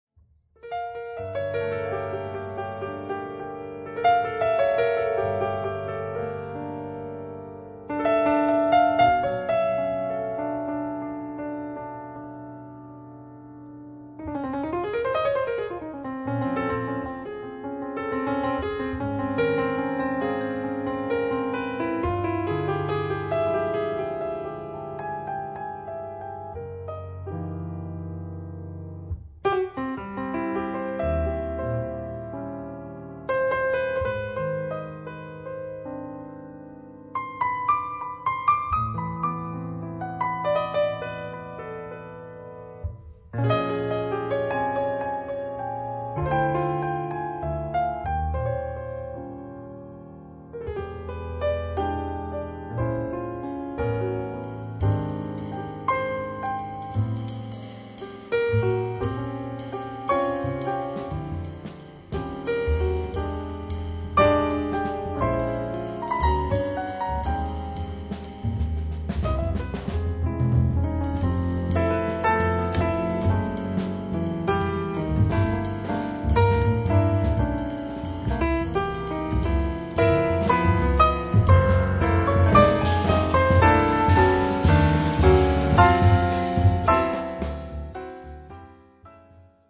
正にブライトなハイセンス北欧ジャズトリオ
piano
double bass
drums